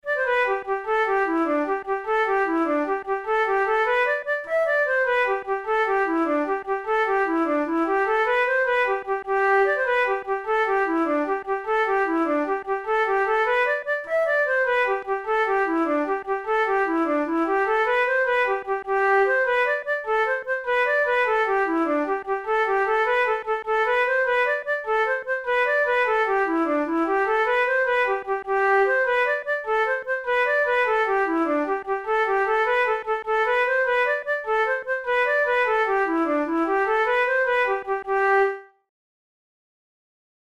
Traditional Irish jig
Categories: Jigs Traditional/Folk Difficulty: easy